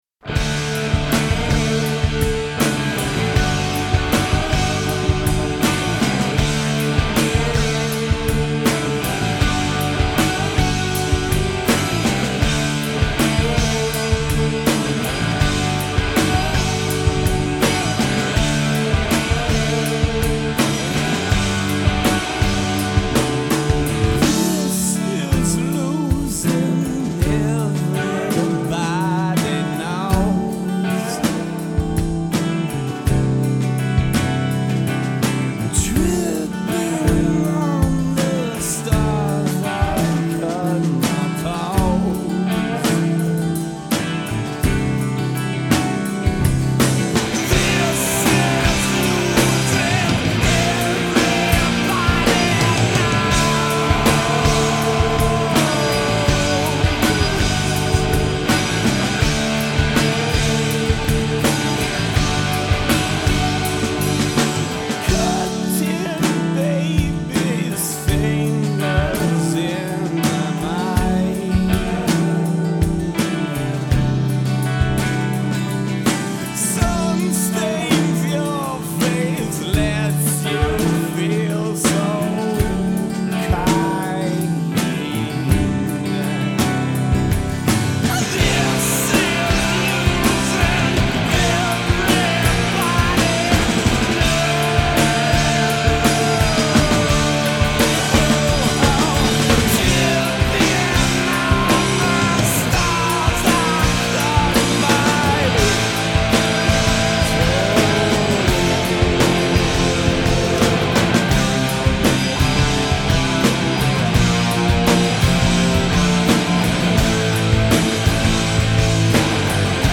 LIVE IN 09!